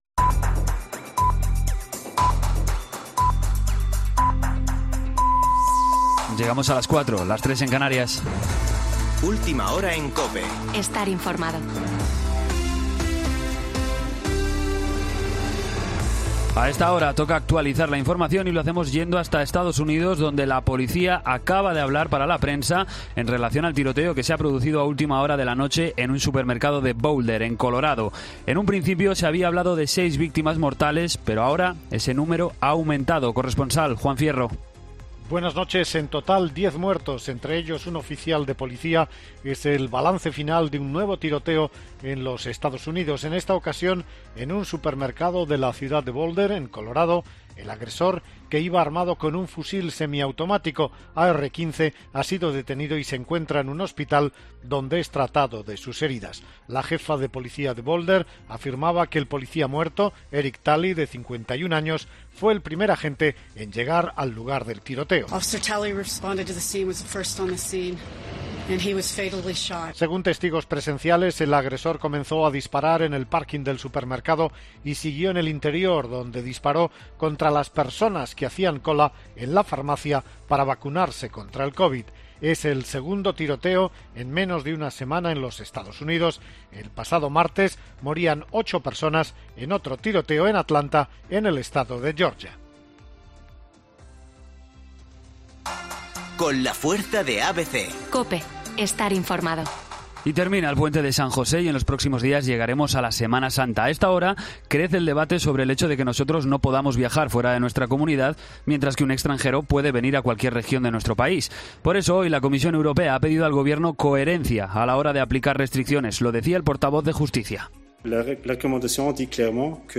Boletín de noticias COPE del 23 de marzo de 2021 a las 04.00 horas